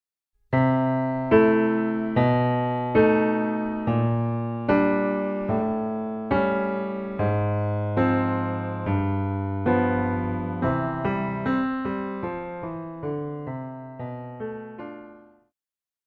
古典,流行
鋼琴
演奏曲
世界音樂
僅伴奏
沒有主奏
沒有節拍器